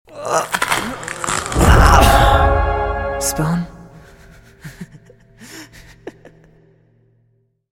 FORSAKEN FANDUB ESPAÑOL LATINO✨